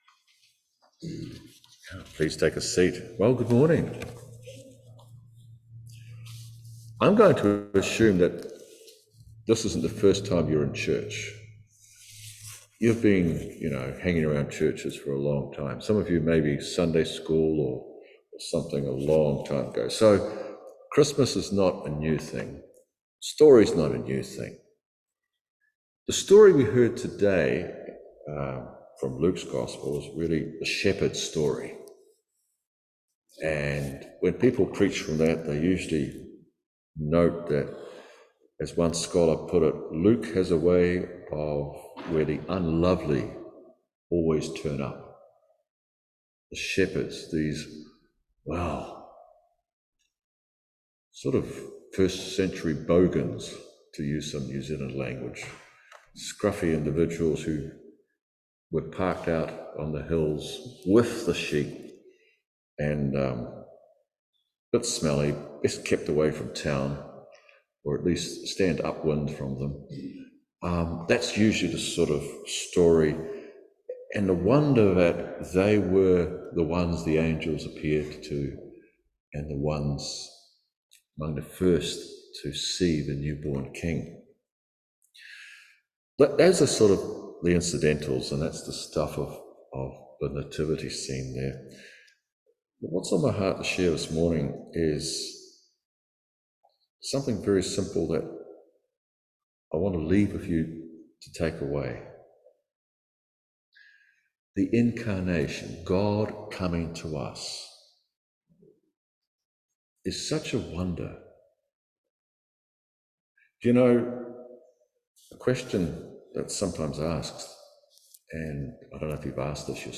Passage: Isaiah 9:2-7, Hebrews 1:1-12, Luke 2:1-20 Service Type: Holy Communion